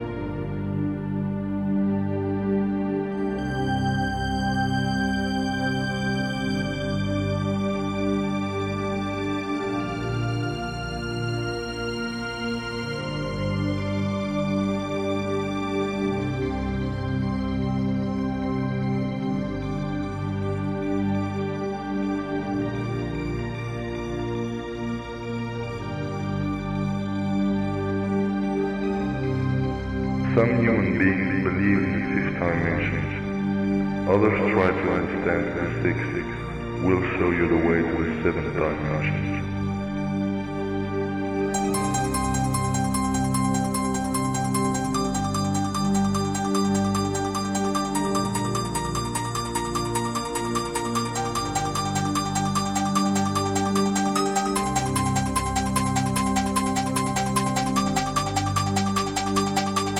Электронная
Музыка в стиле Techno